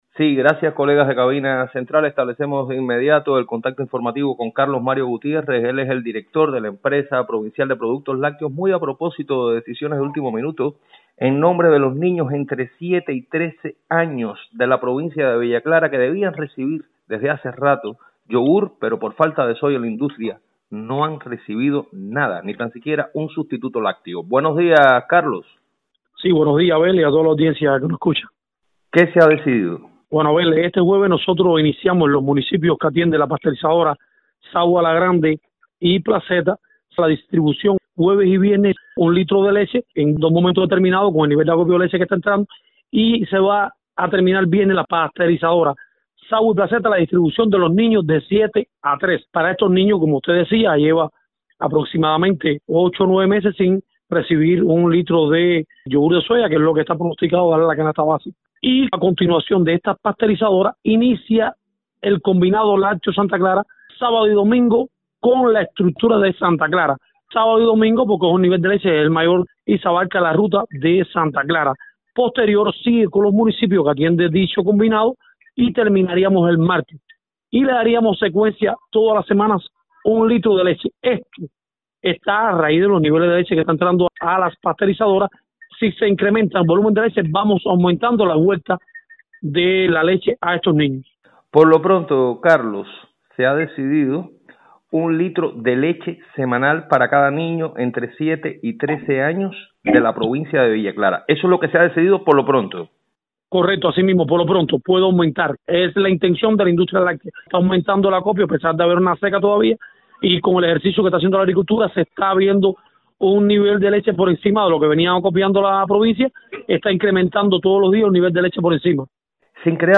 Más detalles usted puede conocerlos en el reporte en audio.